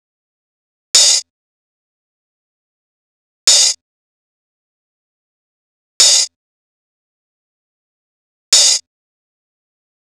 Crashes & Cymbals
SouthSide Cymbol.wav